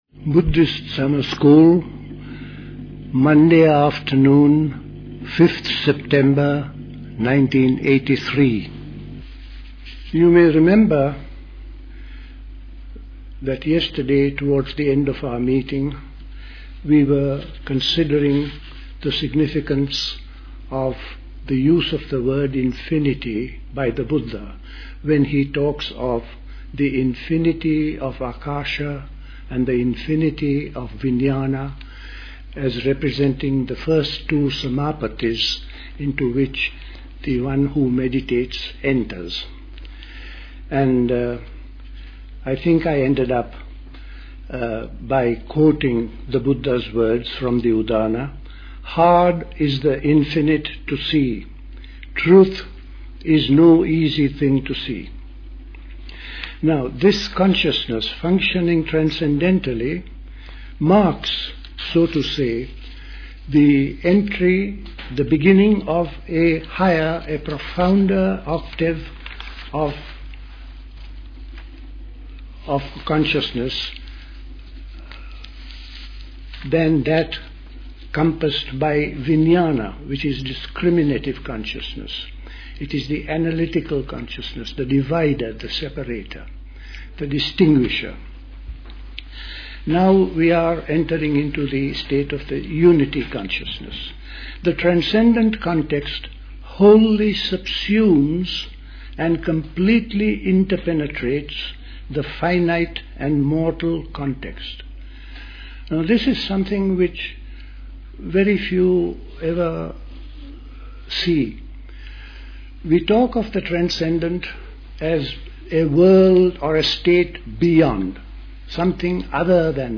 at High Leigh Conference Centre, Hoddesdon, Hertfordshire on 5th September 1983